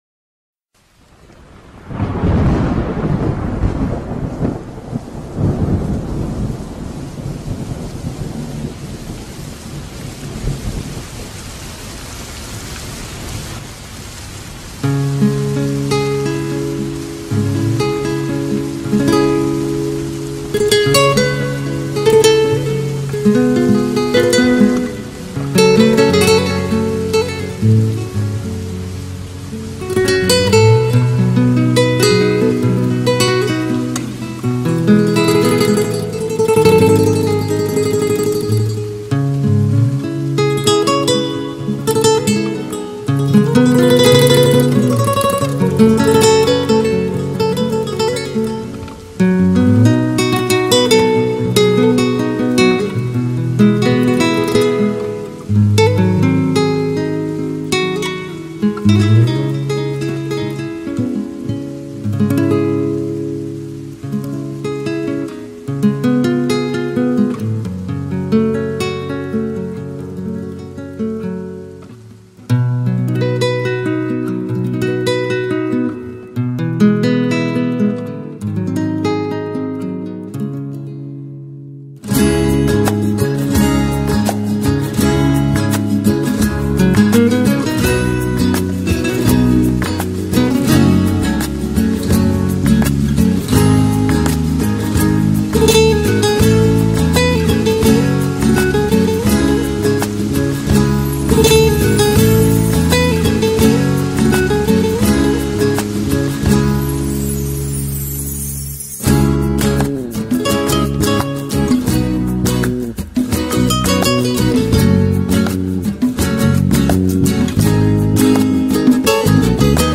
موسیقی گیتار نوازی
guitar.mp3